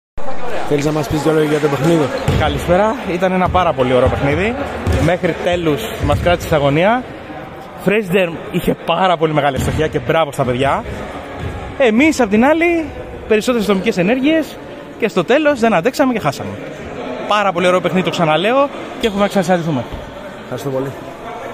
GAME INTERVIEWS:
(Παίκτης Novibet)